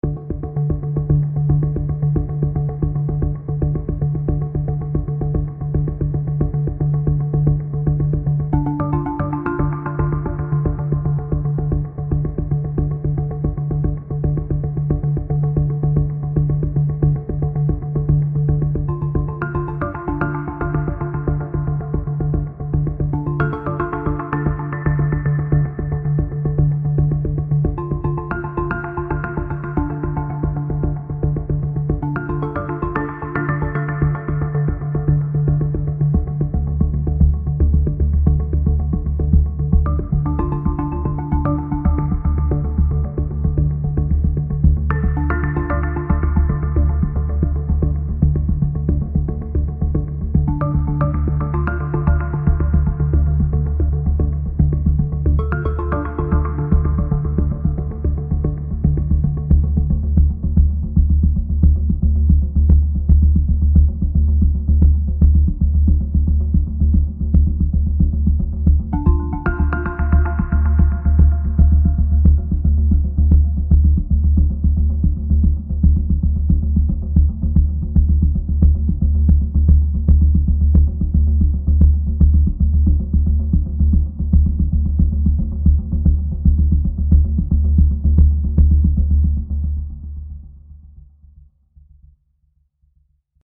without Beat